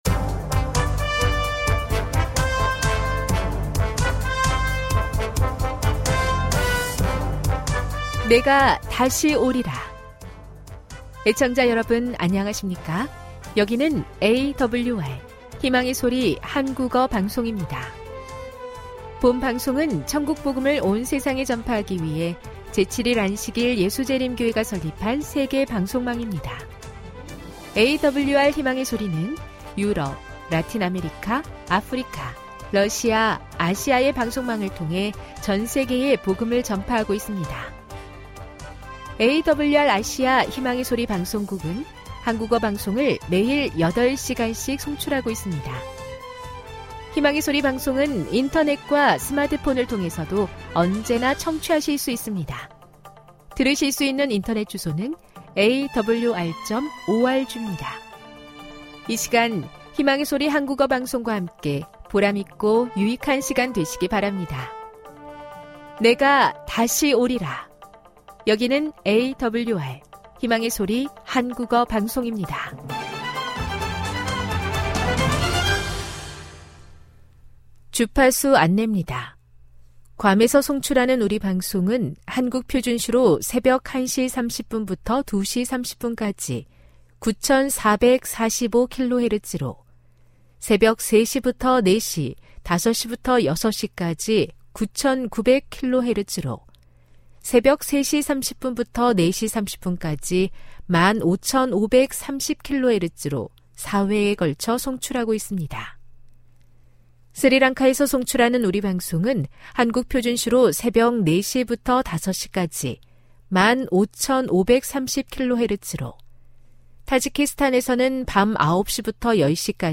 설교, 난해 성경절해설